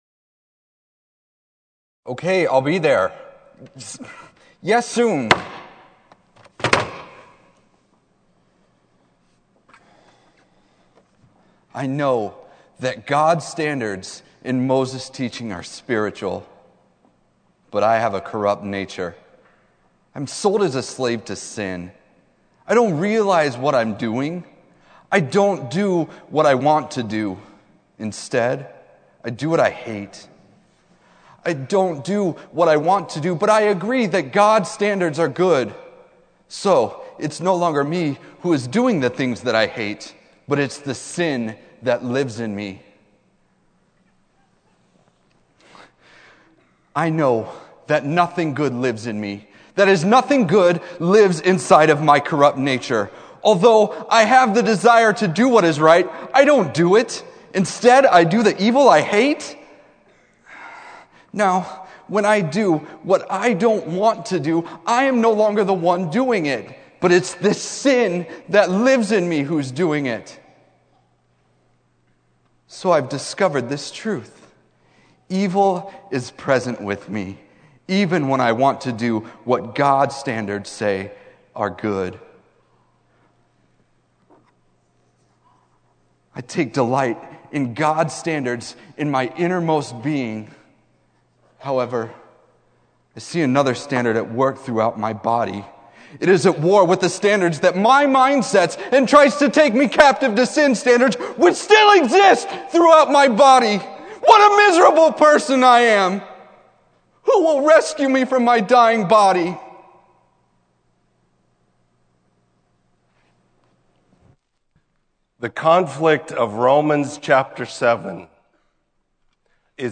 Sermon: The Spirit of Life